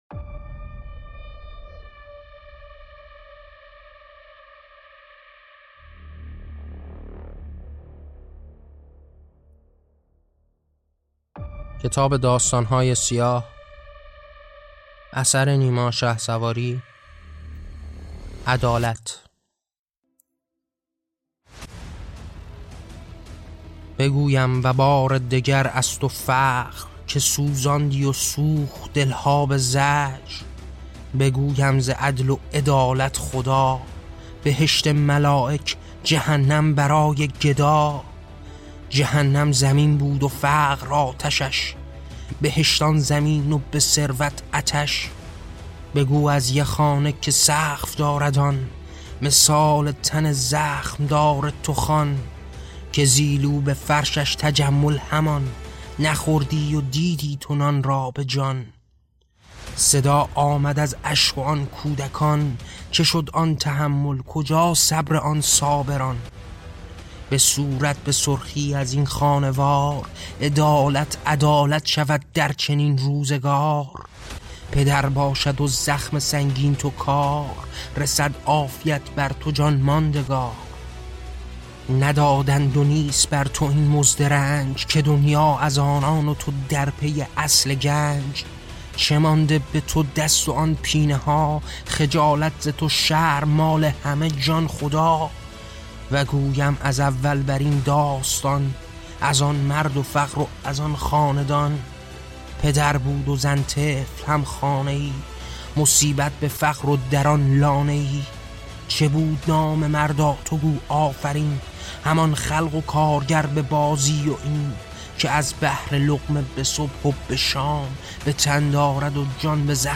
داستان صوتی